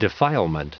Prononciation du mot defilement en anglais (fichier audio)
Prononciation du mot : defilement